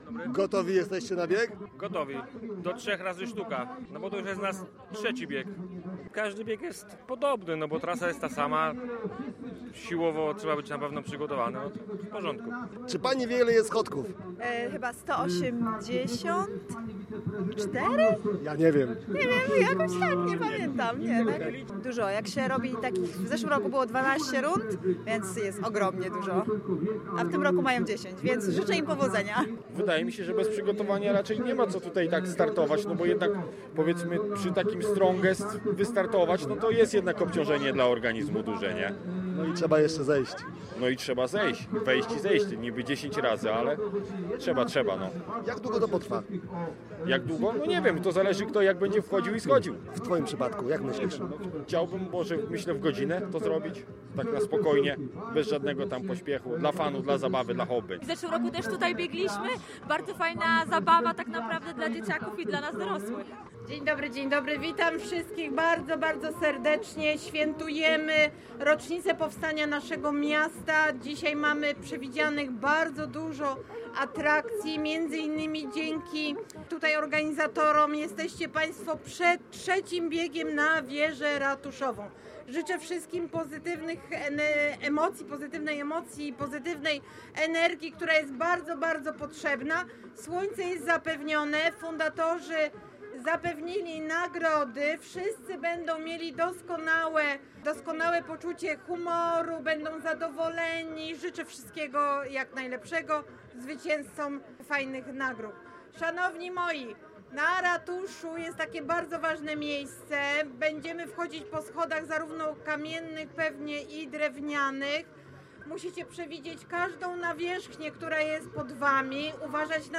Posłuchaj relacji z wydarzenia: